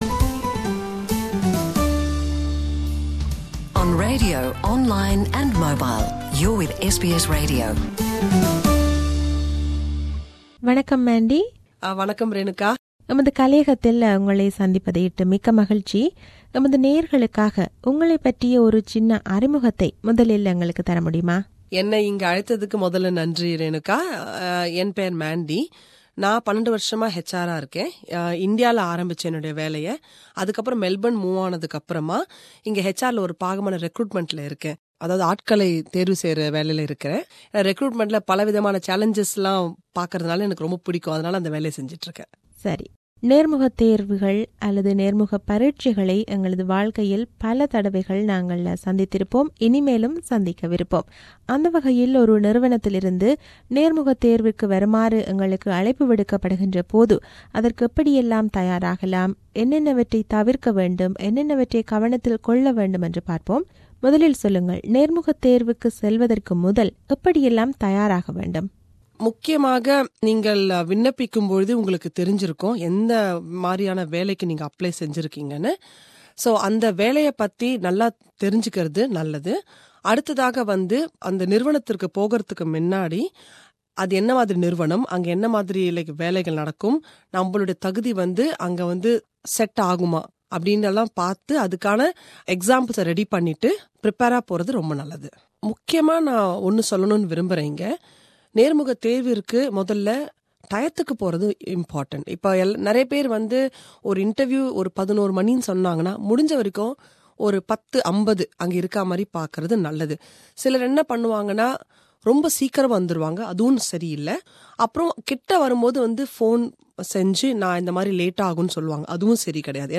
This interview provides tips and advice on preparing for and attending interviews.